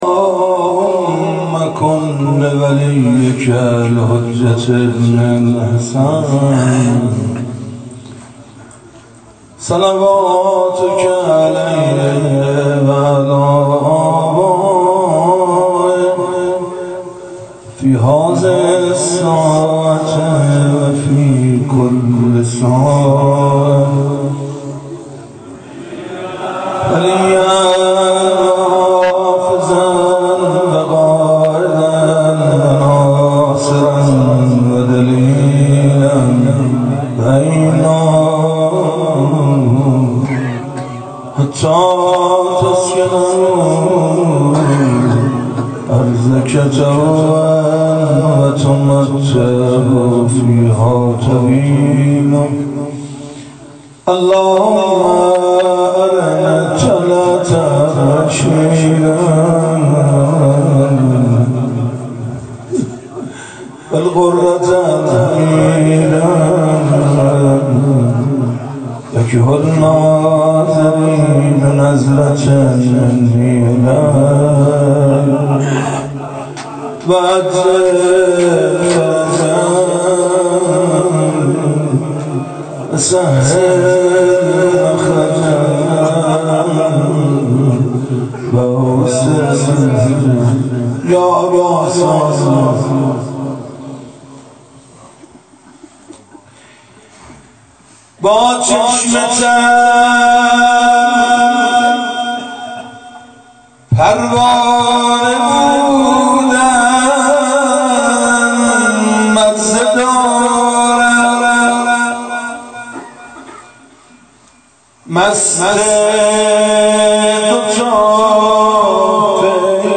روضه خوانی
مراسم عزاداری صبح اربعین